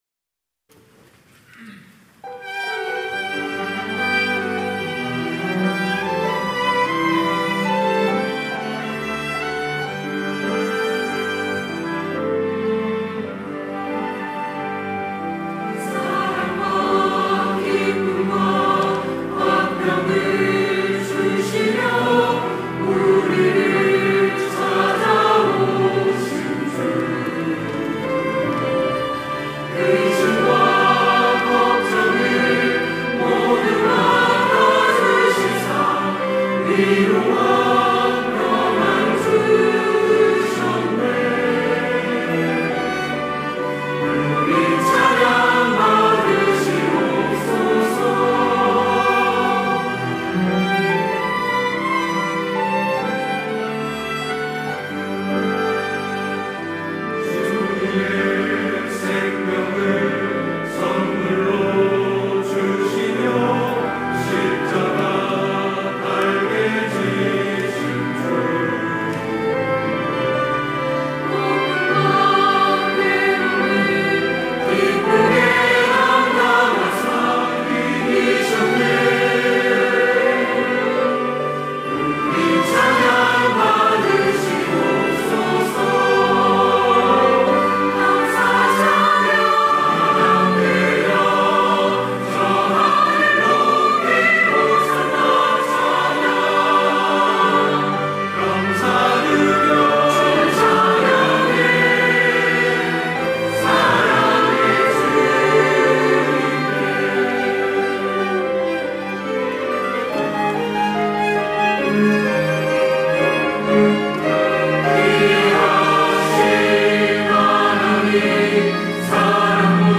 호산나(주일3부) - 우리 찬양 받으시옵소서
찬양대